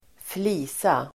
Uttal: [²fl'i:sa]